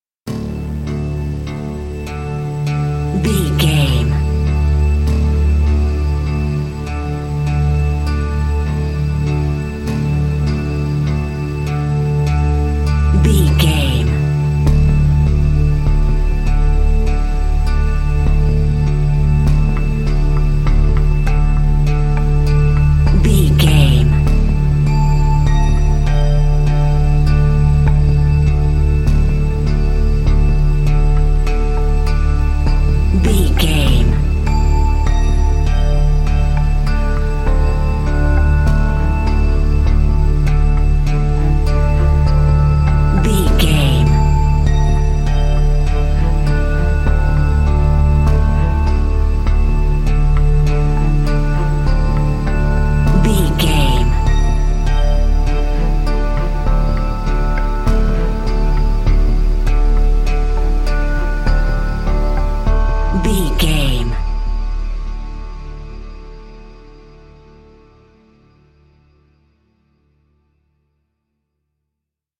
Thriller
Aeolian/Minor
Slow
synthesiser
cello
electric piano